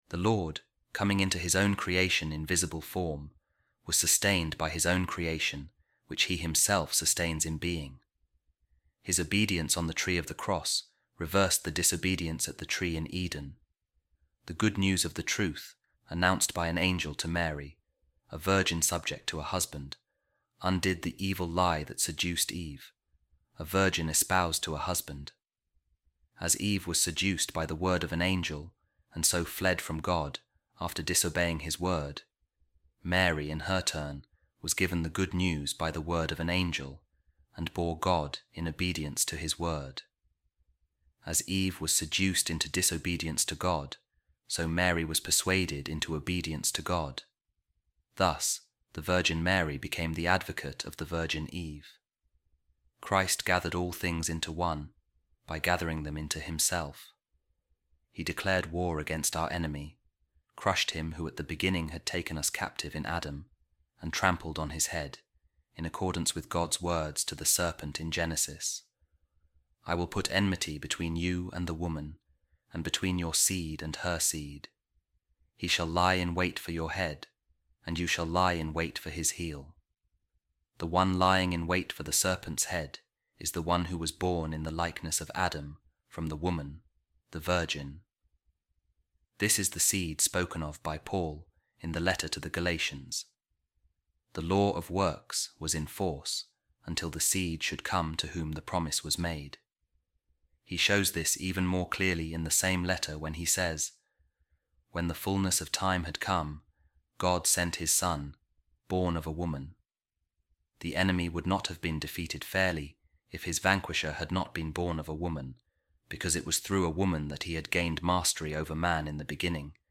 A Reading From The Treatise Of Saint Irenaeus Against The Heresies | On Eve And Mary